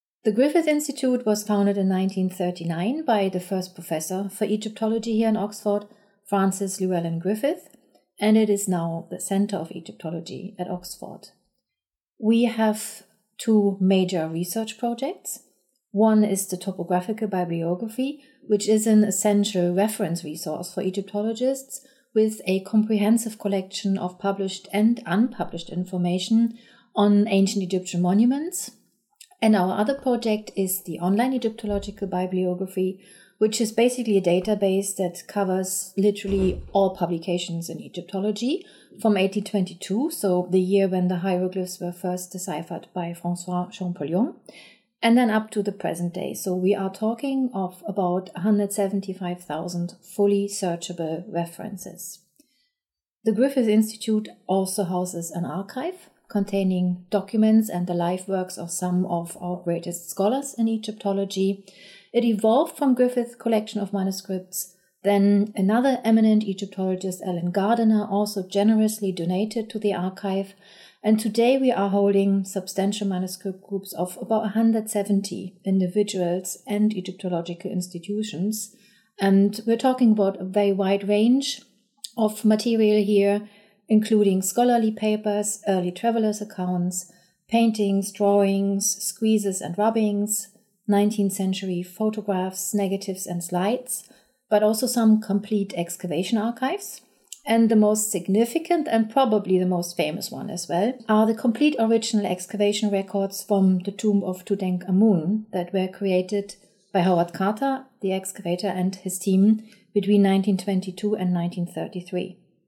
1. Can you tell us about the Griffith Institute?